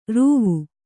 ♪ rūvu